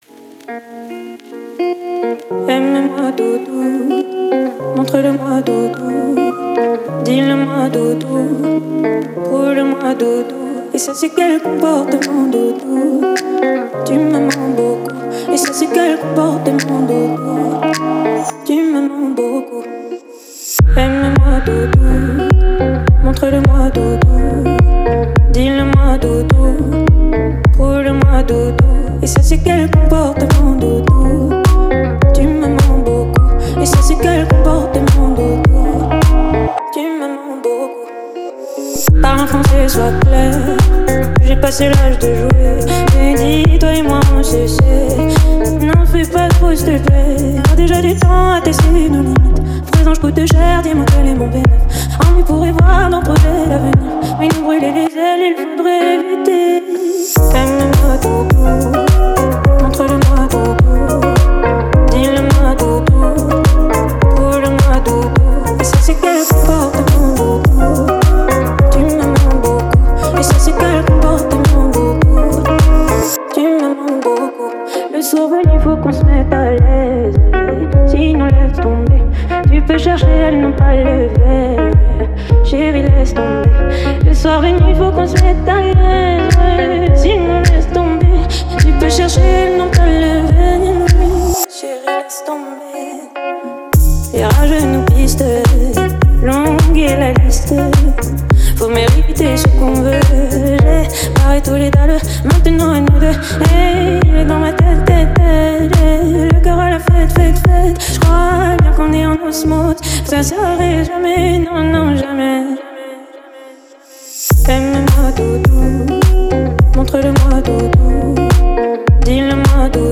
это яркая и мелодичная песня в жанре поп с элементами R&B